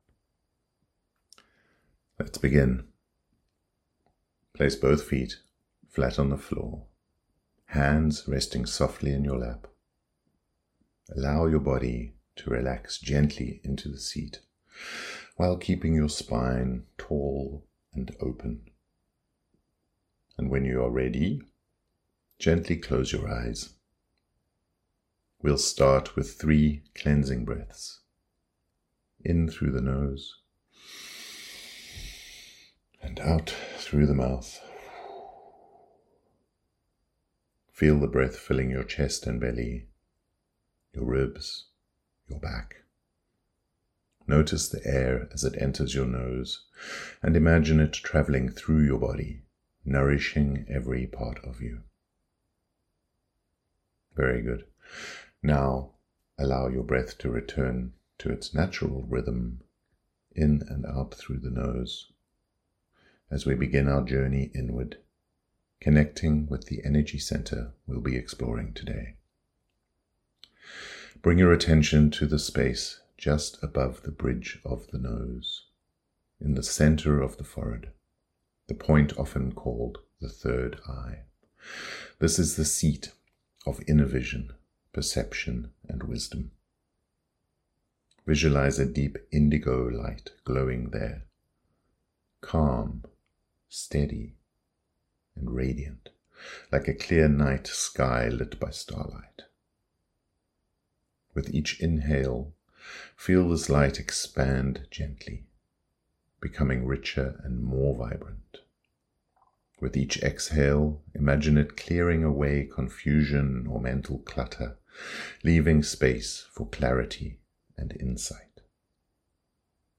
Third Eye Chakra Meditation
CH06-meditation.mp3